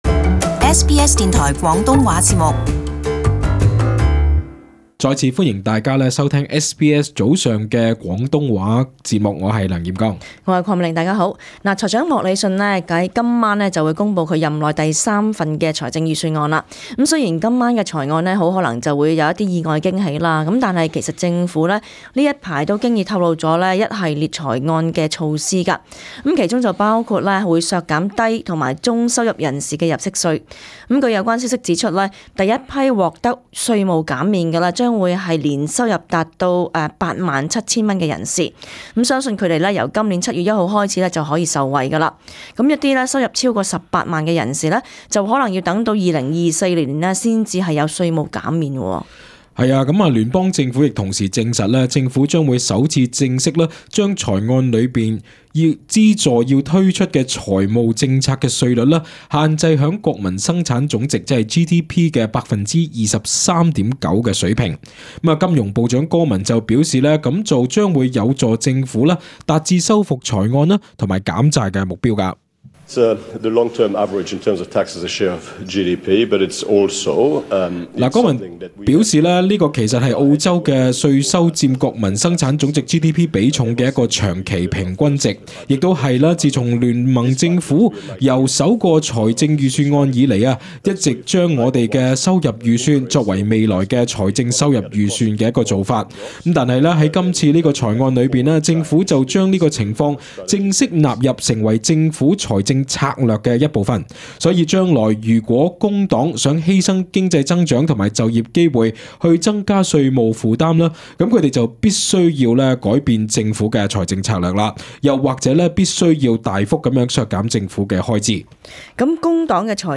【時事報導】聯邦今晚公布新財案